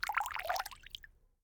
water-splash-05
bath bathroom bubble burp click drain dribble dripping sound effect free sound royalty free Nature